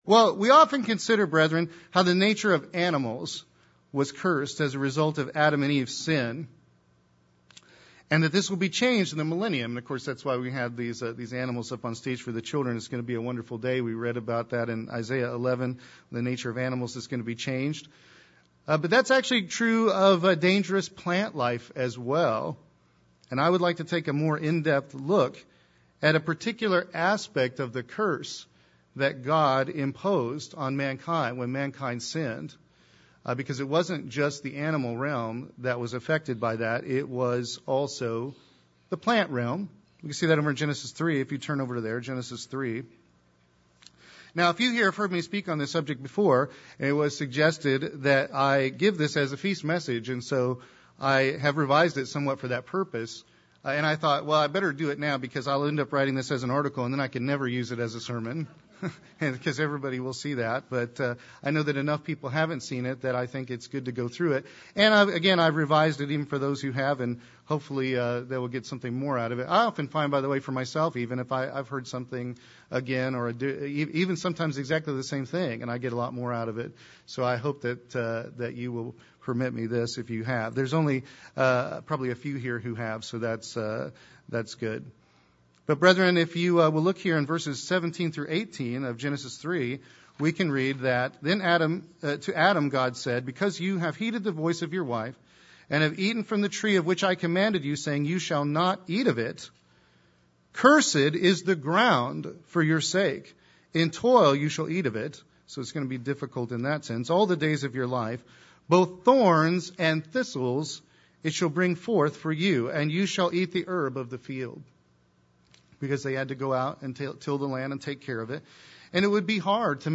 This sermon was given at the Branson, Missouri 2014 Feast site.